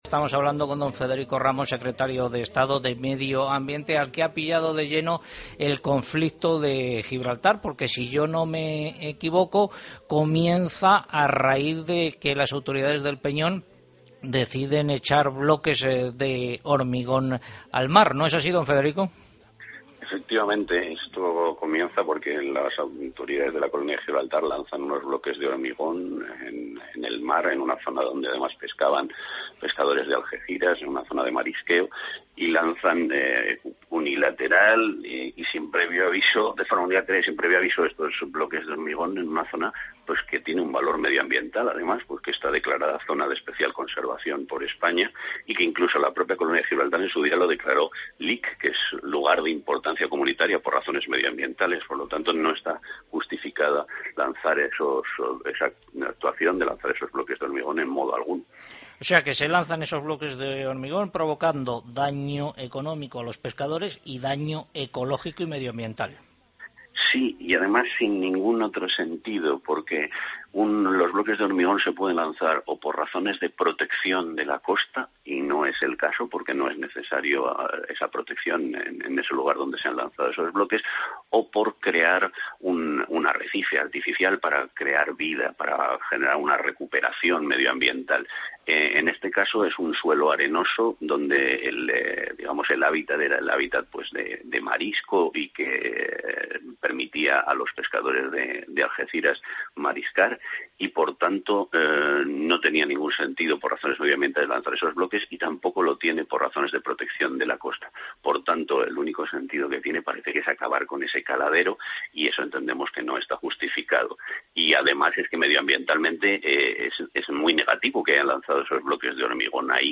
Entrevista a Federico Ramos, secretario de Estado de Medio Ambiente